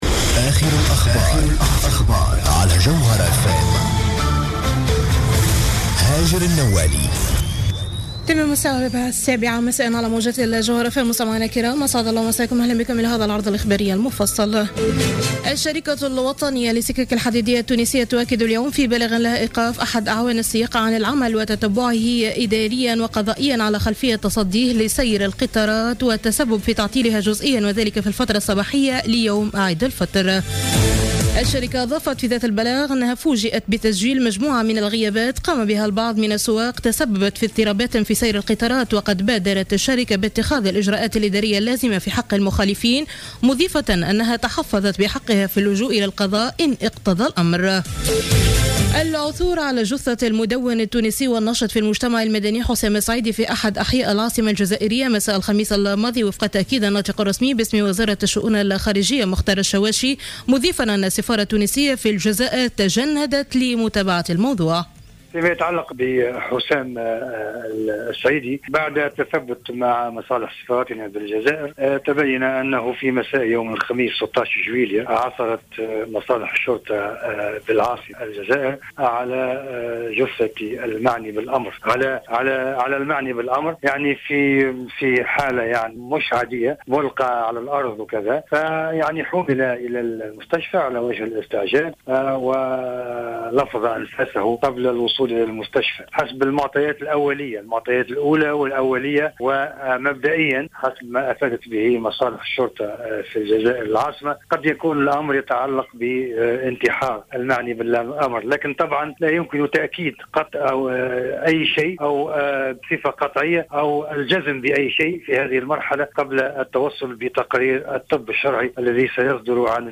نشرة أخبار السابعة مساء ليوم السبت 18 جويلية 2015